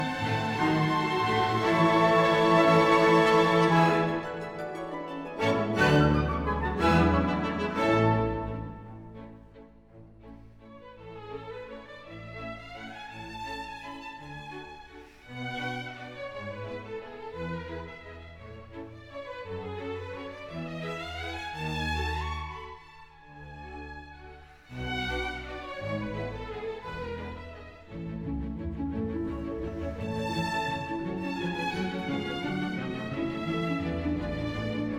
Жанр: Классика